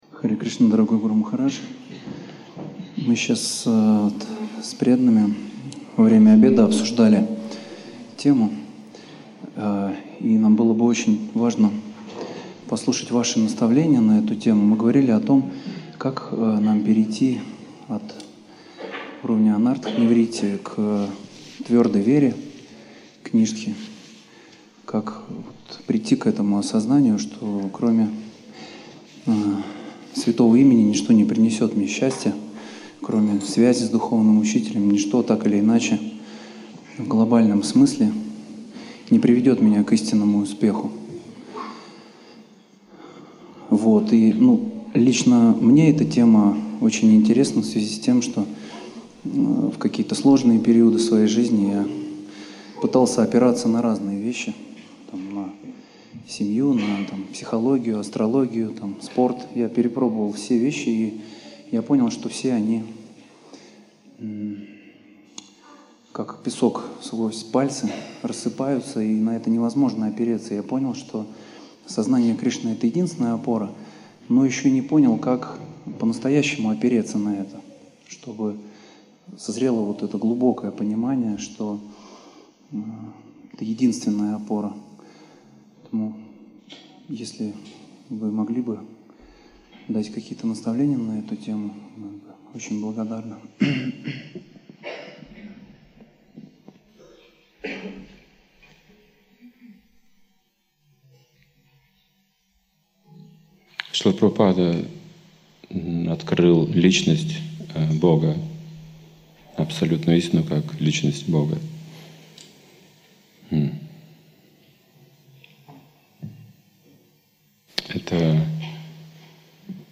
Ответы на вопросы